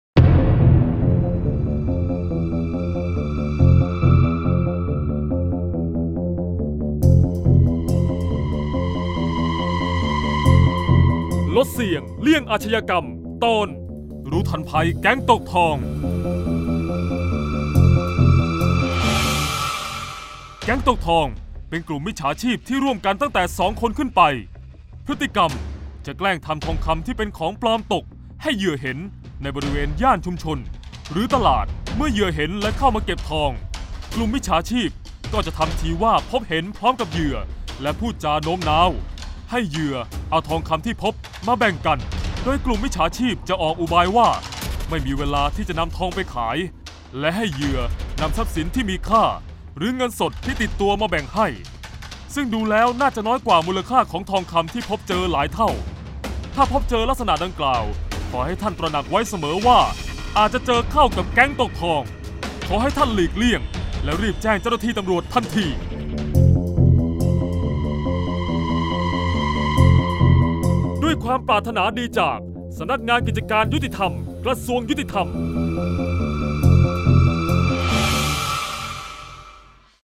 เสียงบรรยาย ลดเสี่ยงเลี่ยงอาชญากรรม 11-ระวังภัยแก๊งตกทอง